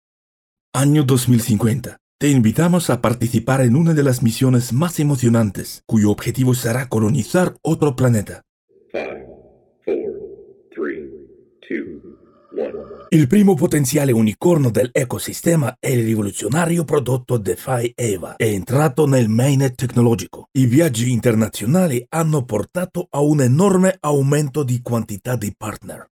Профессиональная начитка на русском, итальянском и испанском языках.
Тракт: Микрофон AKG c214, аудиоинтерфейс RME Babyface Pro, DAW Samplitude